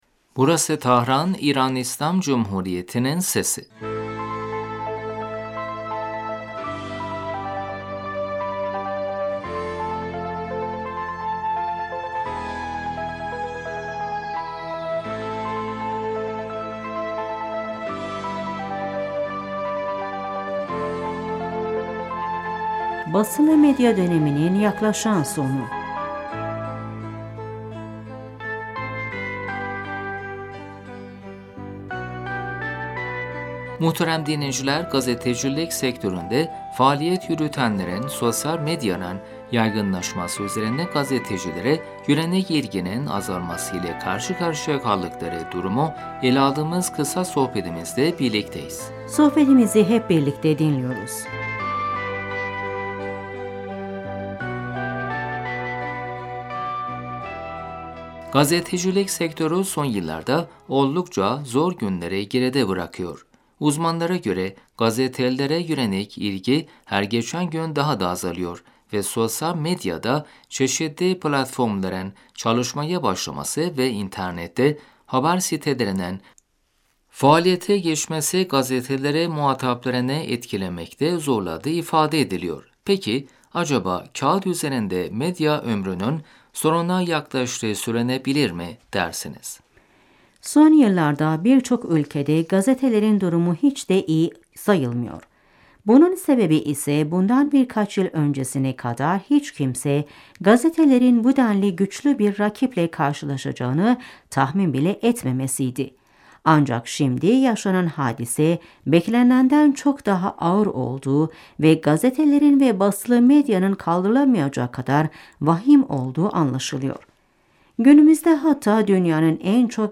gazetecilik sektöründe faaliyet yürütenlerin sosyal medyanın yaygınlaşması üzerine gazetelere yönelik ilginin azalması ile karşı karşıya kaldıkları durumu ele aldığımız kısa sohbetimizde birlikteyiz.